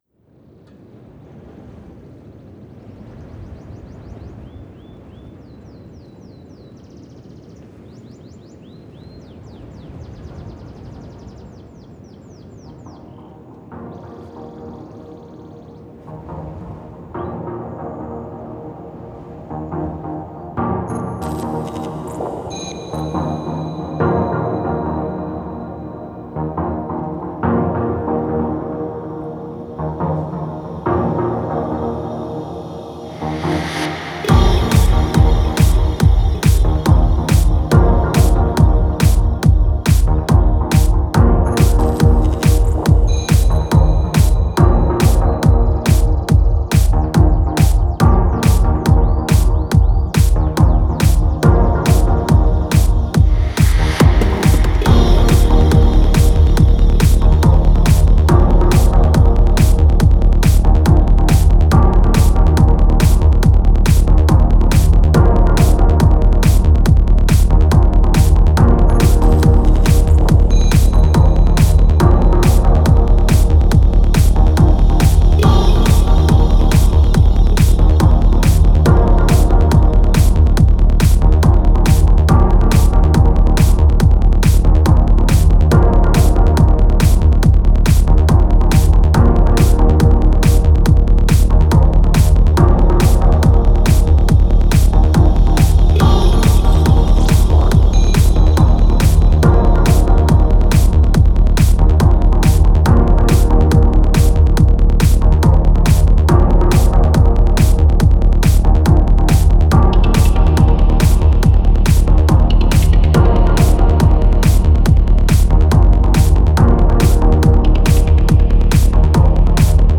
With artist friends, I’ve also created an audio track and sound collage opening with the sound of canary song – inspired by how refugees fleeing religious persecution arriving in Norwich in 16th century gave Norwich’s football team their name. Children with lived experience of conflict shared words from The Rainbow Chrysalis as a call for peace and made art celebrating these beautiful butterflies. The track also takes inspiration from the heritage of unity in rave in British culture – a huge part of East Anglian musical heritage.